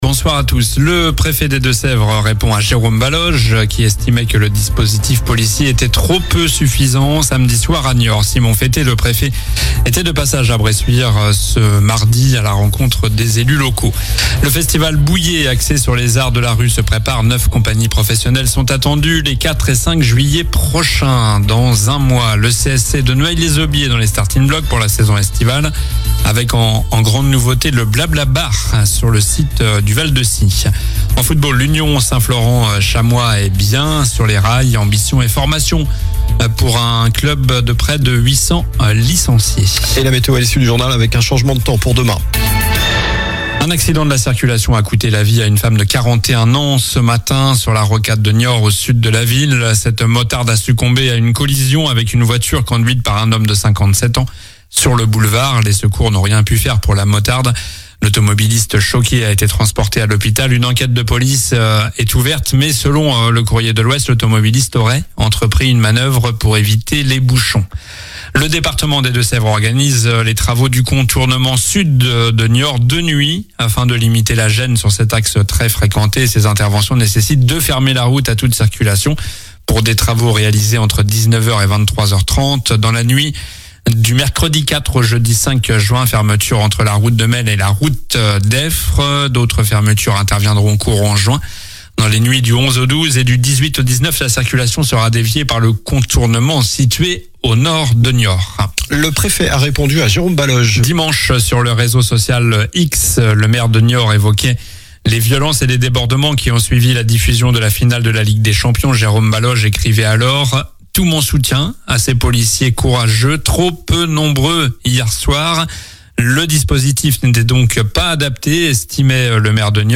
Journnal du mardi 03 juin (soir)